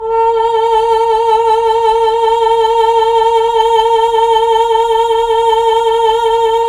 VOX_Chb Fm A_5-L.wav